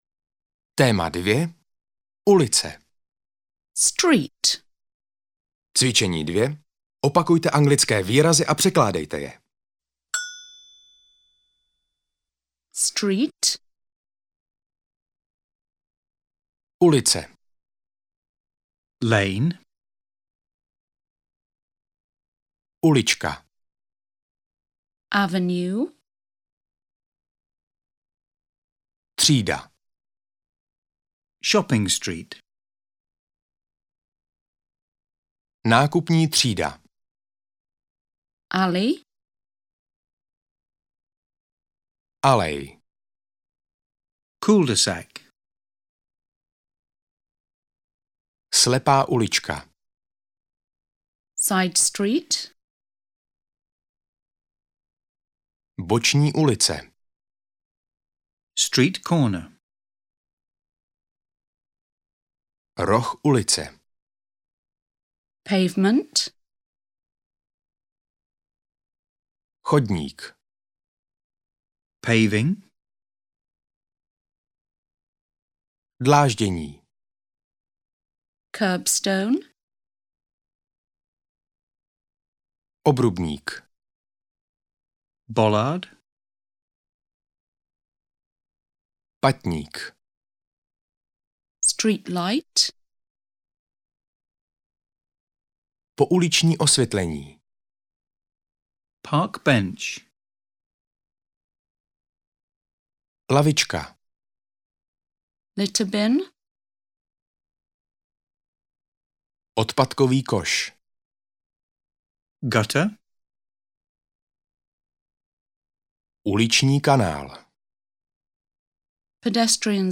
Ukázka z knihy
• InterpretRôzni Interpreti